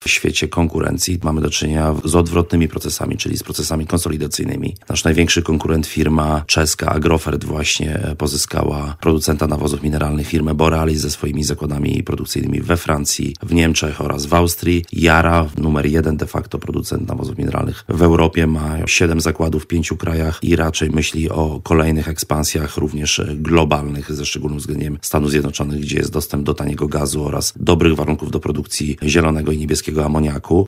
- Nie jesteśmy w dobrej sytuacji, za siedem kwartałów mamy skumulowaną stratę EBITDA przekraczającą 1,5 miliarda złotych - mówił w porannej rozmowie Radia